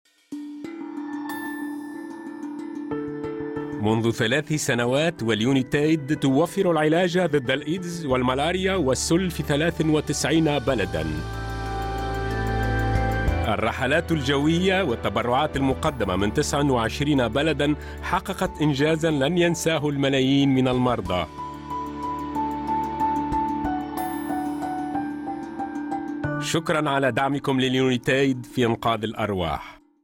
Voix pour L' UNESCO